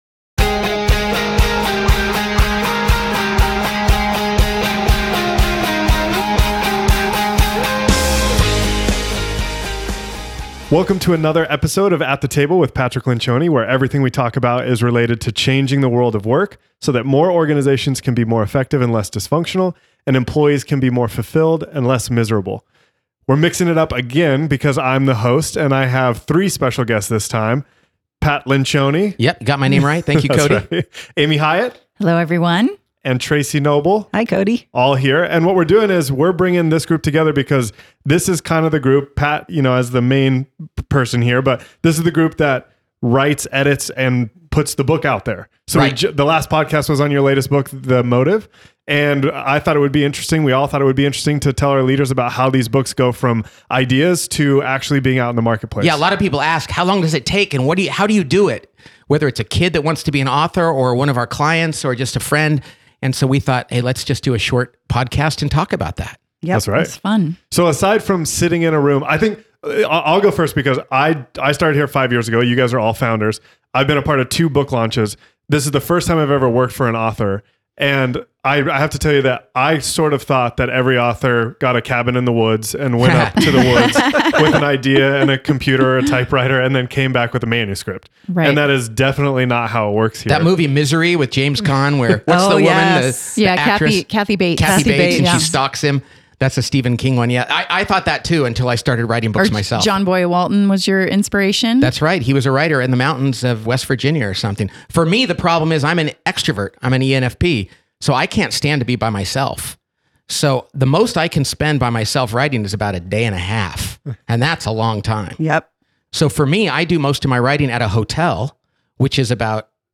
Pat and the team sit down to discuss the book writing process and tell some of their favorite stories from the last 20 years and 12 books.